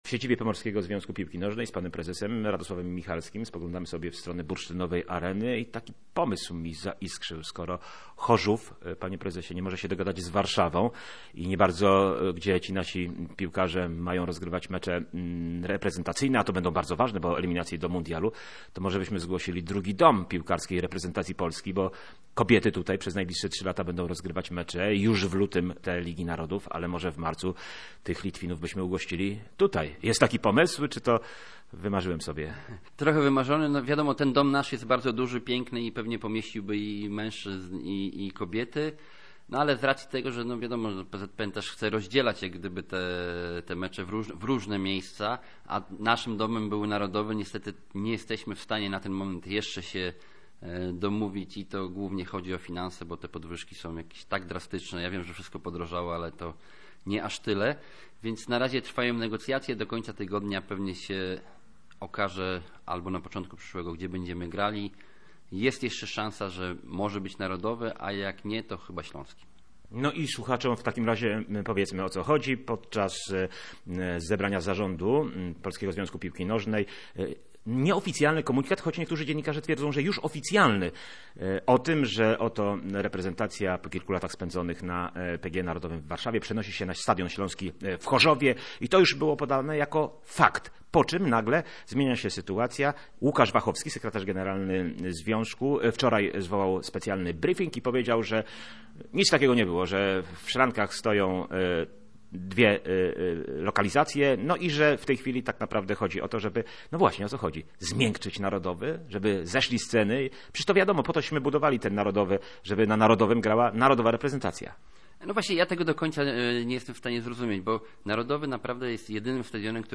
Pod koniec marca biało-czerwoni rozegrają dwa pierwsze mecze eliminacyjne do mundialu 2026 z Litwą i Maltą. Najpóźniej na początku przyszłego tygodnia trzeba wskazać właściwy adres i przesłać go do UEFA – mówił w Radiu Gdańsk w paśmie Sportowa Środa członek zarządu PZPN